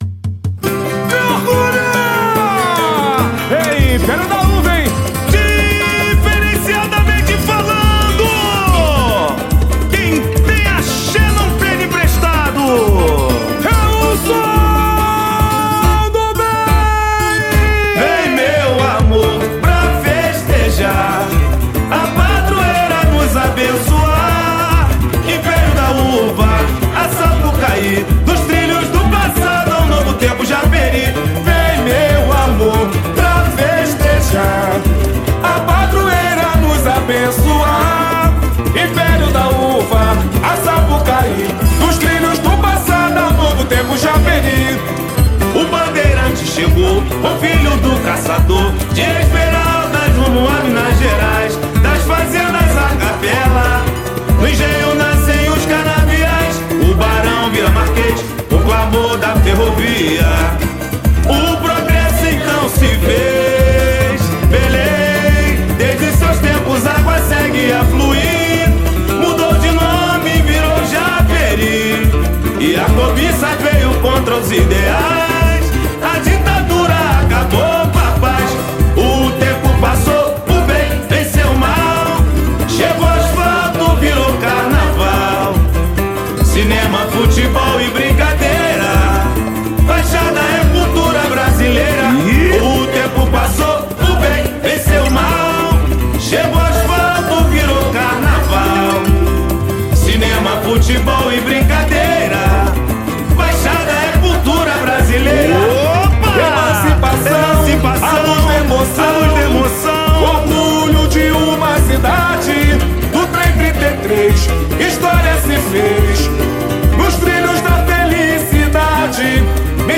Autores do Samba-Enredo
Violão